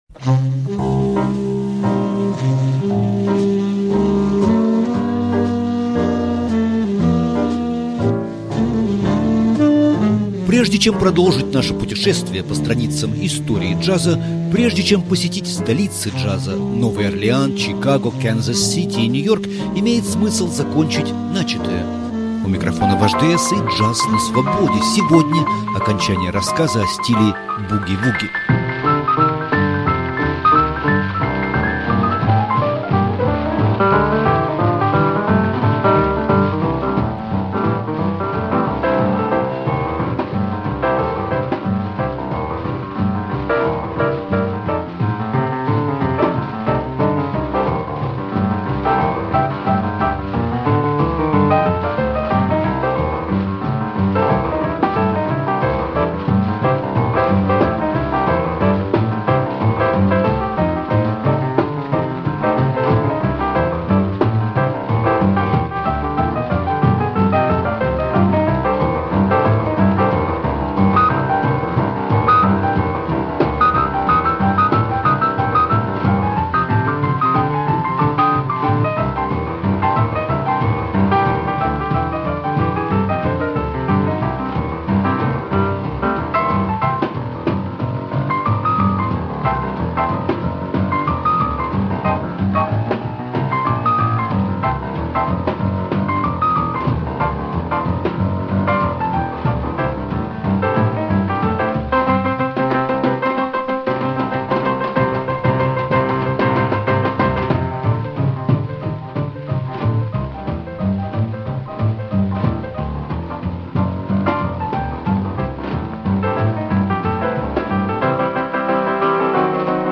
Джаз на Свободе №29. Пианистки стиля буги-вуги